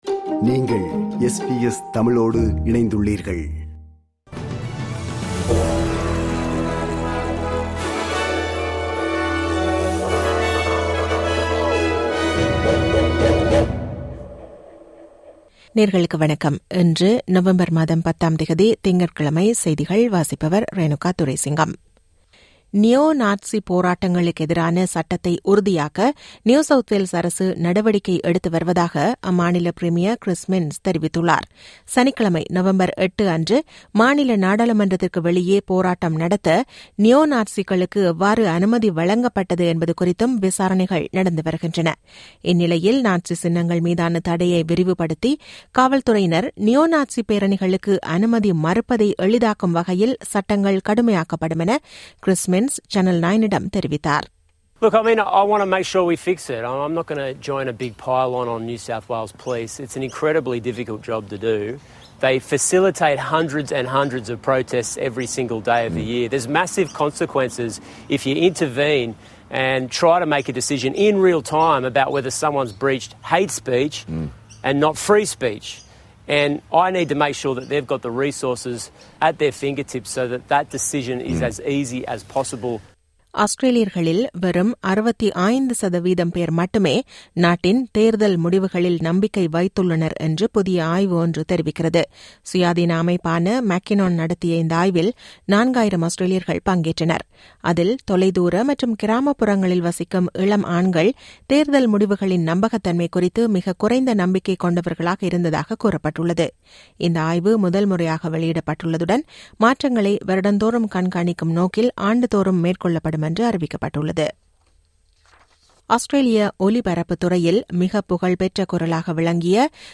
இன்றைய செய்திகள்: 10 நவம்பர் 2025 - திங்கட்கிழமை
SBS தமிழ் ஒலிபரப்பின் இன்றைய (திங்கட்கிழமை 10/11/2025) செய்திகள்.